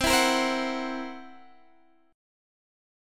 Listen to C7b5 strummed